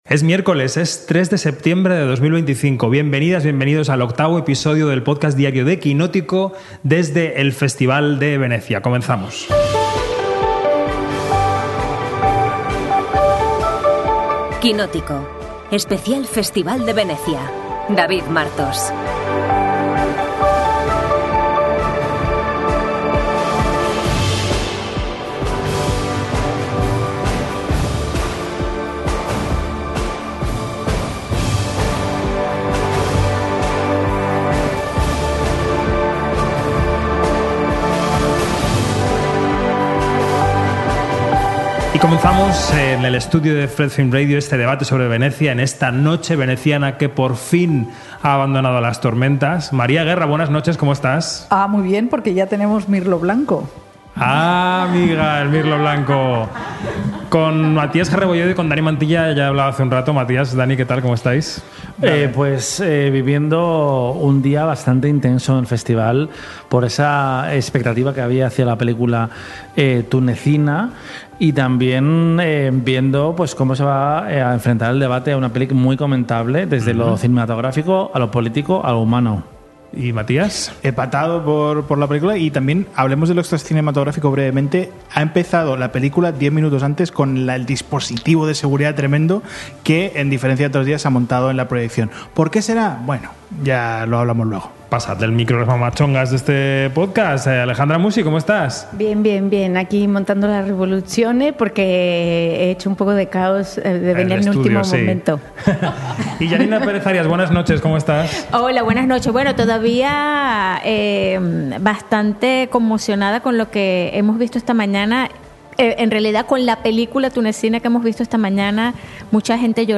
Desde la Mostra de Venecia, ¡todo lo que necesitas saber, todos los días!